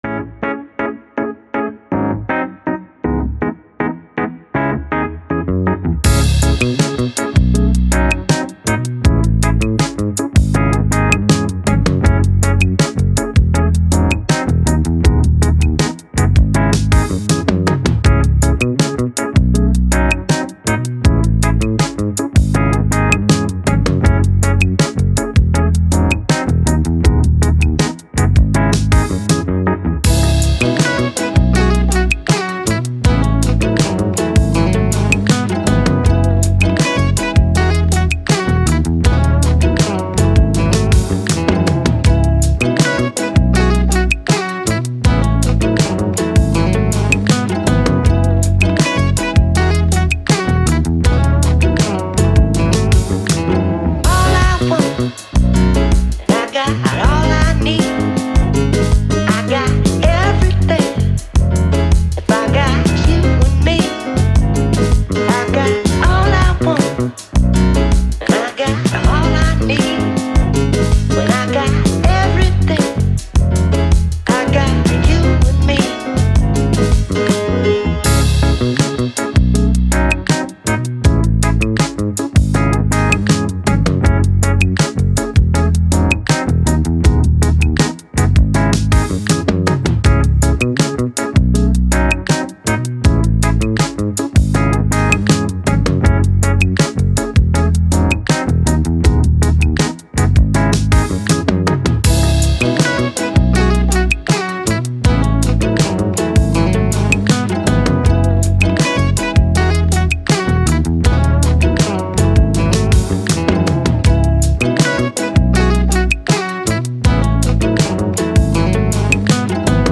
Funk, Hip Hop, Positive, Vocal